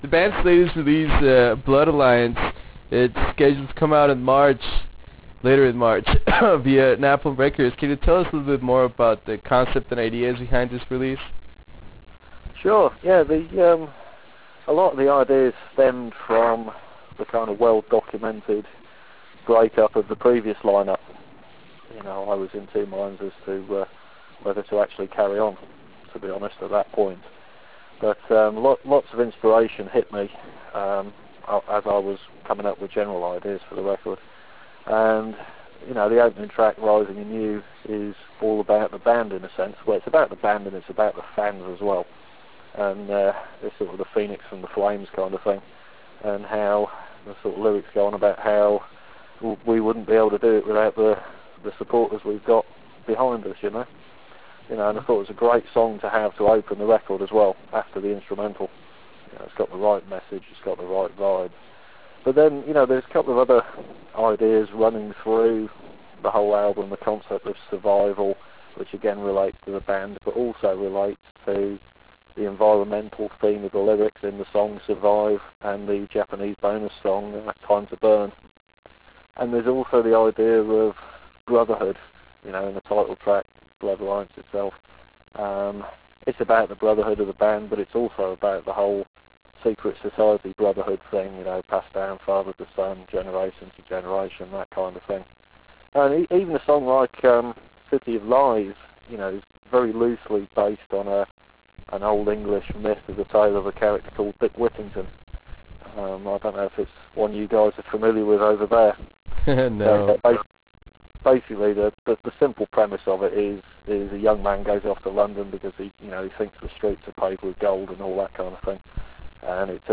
In this 25+ minute long interview we discuss the new album in detail and talk about the new re-vamped lineup. In this interview we also talk about what is in store for this new Power Quest era and what can the fans expect when seeing them live.